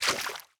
Fantasy_Game_Footsteps_Water_2.ogg